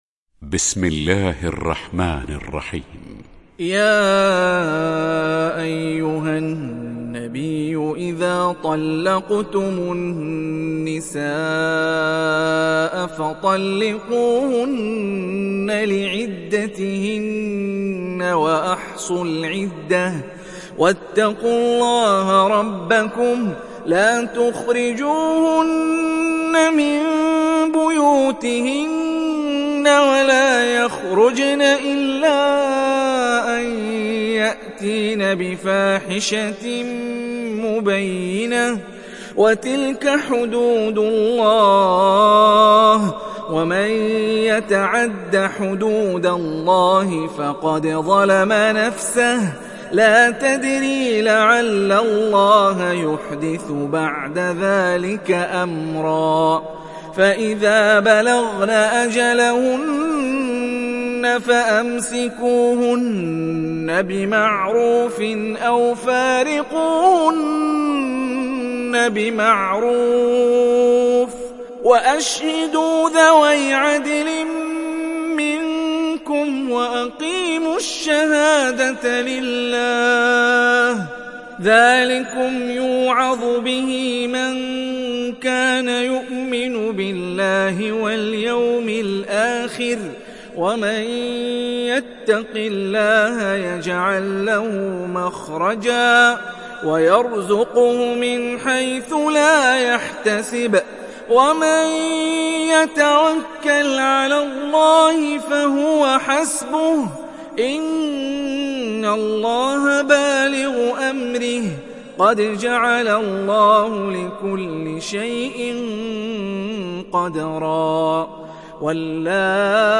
تحميل سورة الطلاق mp3 بصوت هاني الرفاعي برواية حفص عن عاصم, تحميل استماع القرآن الكريم على الجوال mp3 كاملا بروابط مباشرة وسريعة